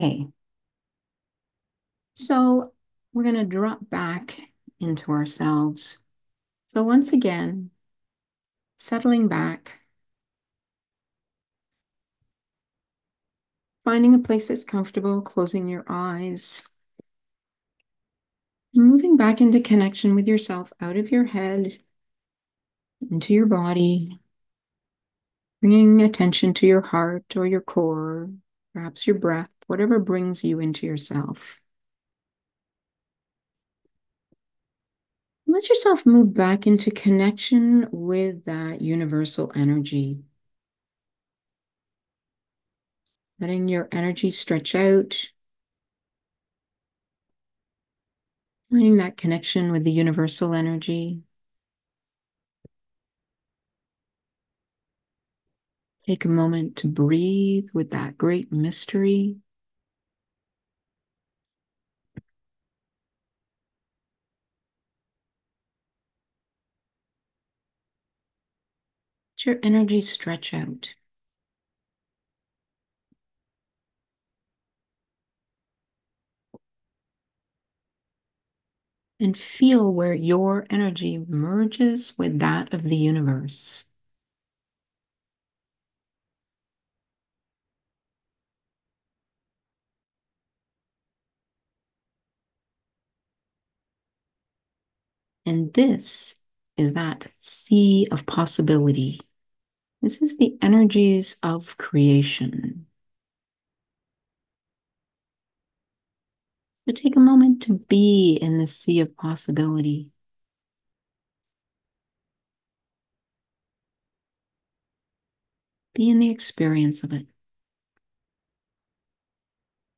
Guided Journey 2
Guided Visualization